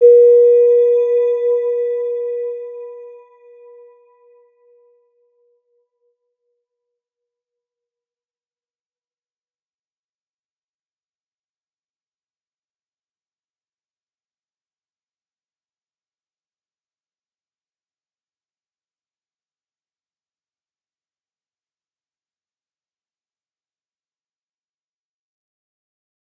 Round-Bell-B4-f.wav